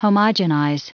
Prononciation du mot homogenize en anglais (fichier audio)
Prononciation du mot : homogenize